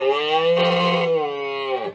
Sound Buttons: Sound Buttons View : Cow 1
cow-1.mp3